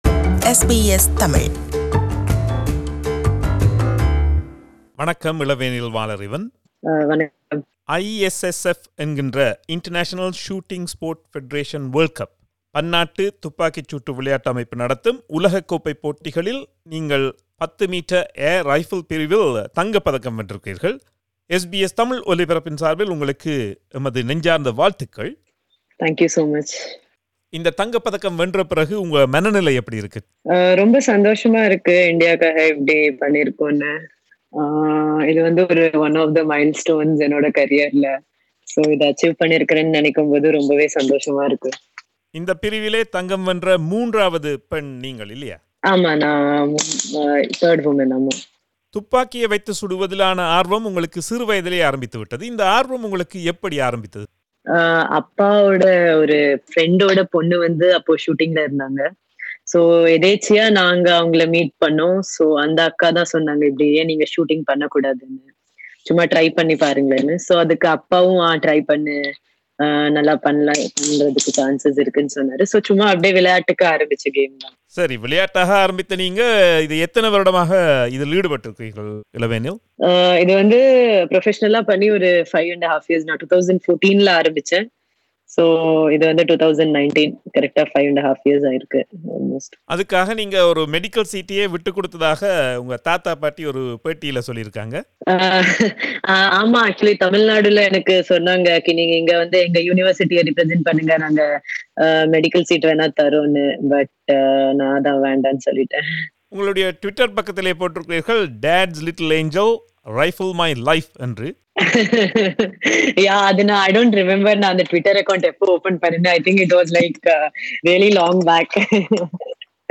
அதில், 20 வயதான இளவேனில் வாலறிவன், 10m Air Rifle பிரிவில் தங்கப் பதக்கத்தை வென்றார். அவரை நேர்கண்டு உரையாடுகிறார்